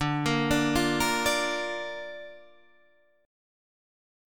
Dm#5 Chord